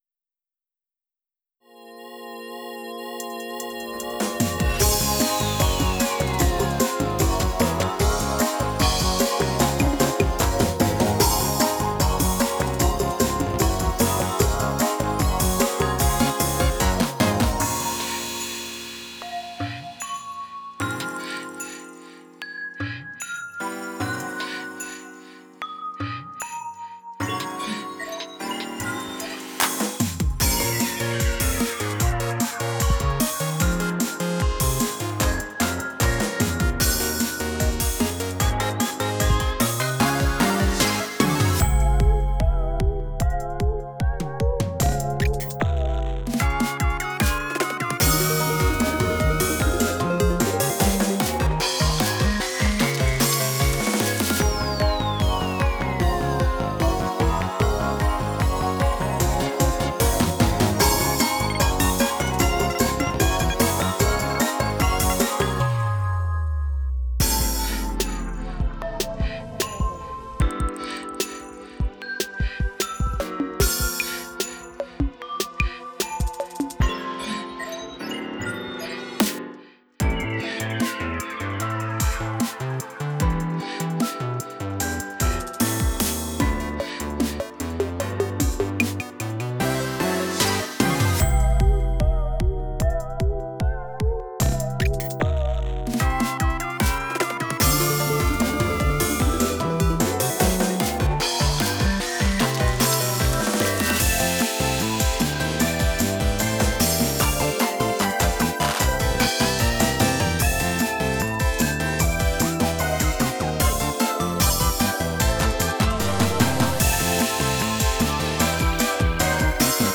BPMは150です
オケ2mix